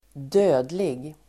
Uttal: [²d'ö:dlig]